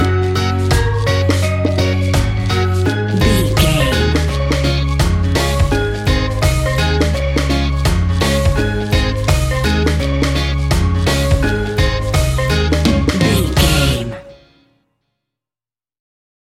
A happy piece of calypso summer sunshine music.
That perfect carribean calypso sound!
Uplifting
Ionian/Major
D♭
steelpan
drums
percussion
bass
brass
guitar